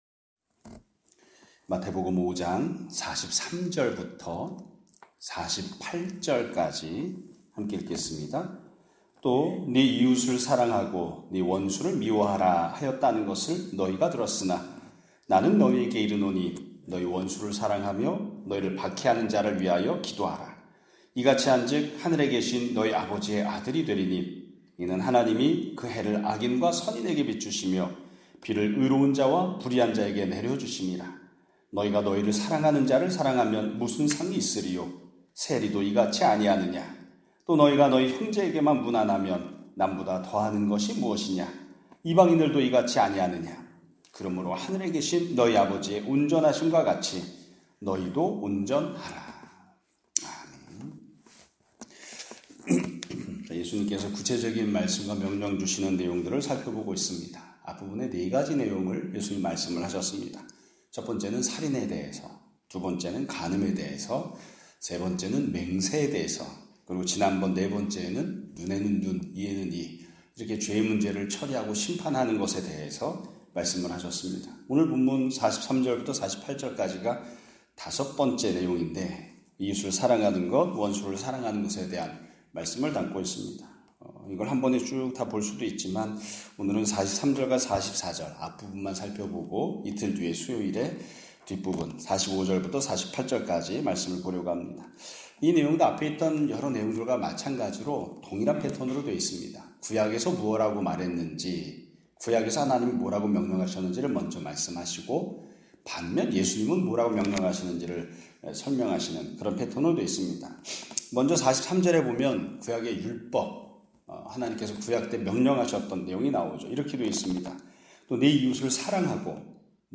2025년 6월 2일(월 요일) <아침예배> 설교입니다.